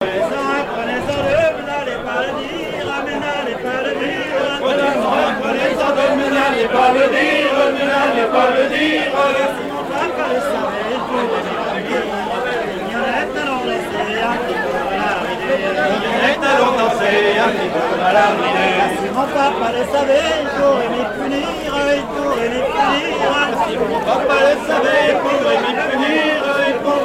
Île-d'Yeu (L')
danse : laridé, ridée
chansons à danser
Pièce musicale inédite